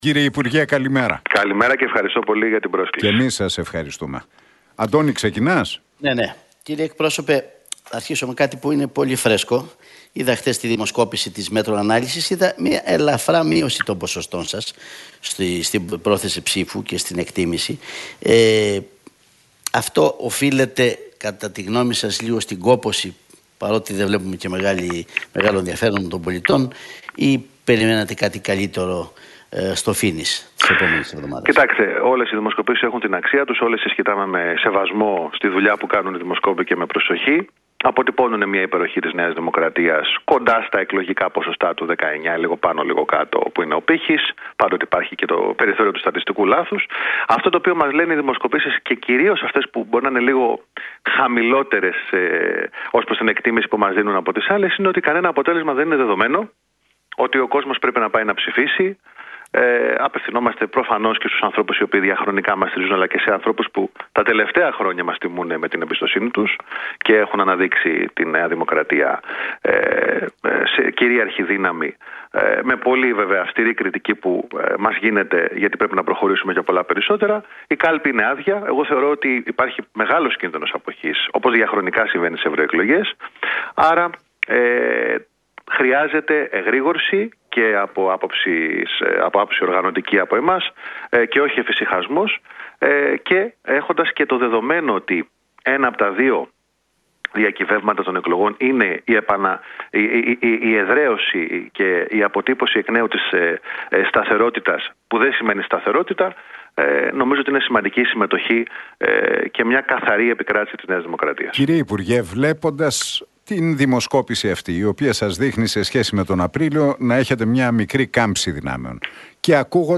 Μαρινάκης στον Realfm 97,8: Ο ΣΥΡΙΖΑ άγεται και φέρεται με έναν μανδύα lifestyle αλλά προτείνει τη χρεοκοπία της χώρας